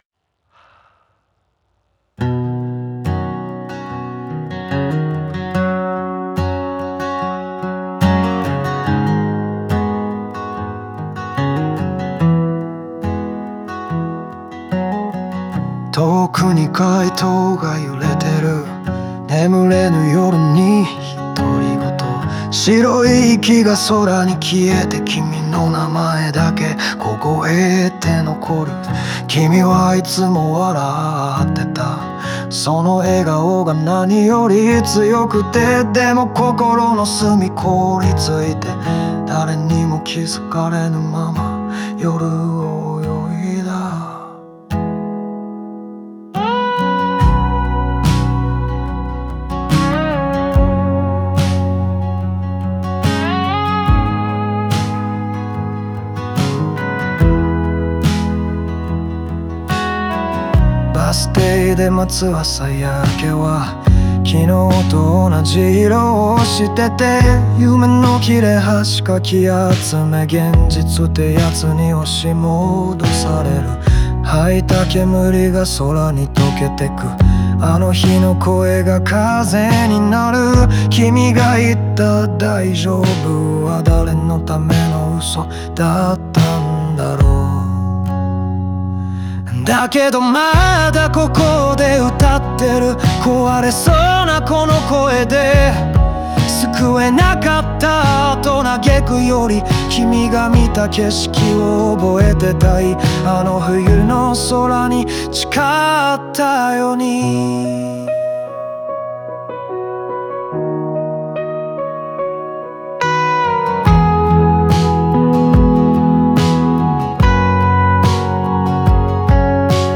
穏やかなアコースティックサウンドが、感情の揺らぎをより深く引き立てます。